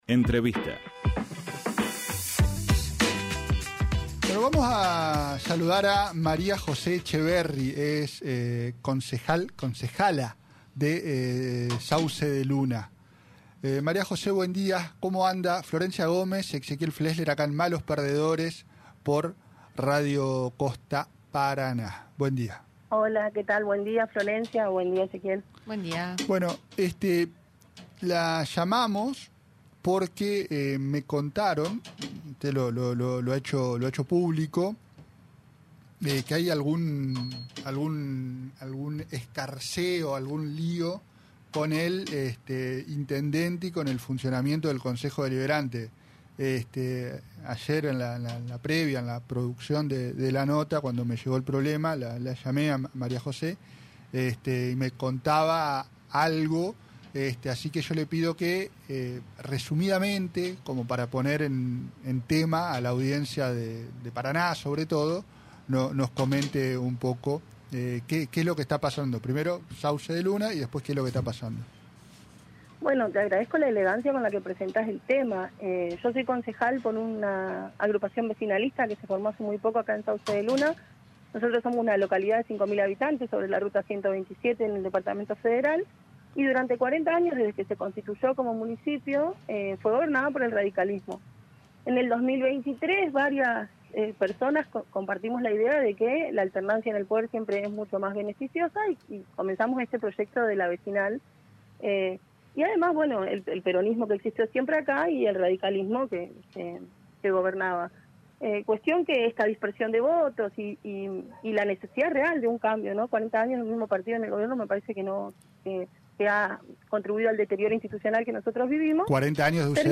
AUDIO ENTREVISTA A CONCEJAL MARÍA JOSÉ ETCHEVERRY
María José Etcheverry, concejal por la Unión Vecinal de Sauce de Luna.